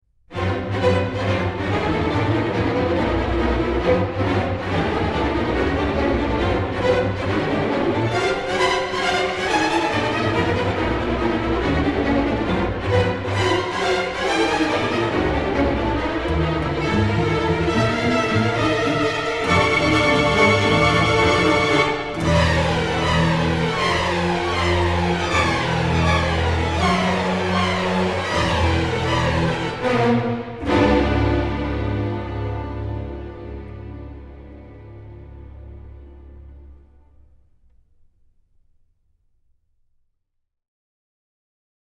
driving, churning segment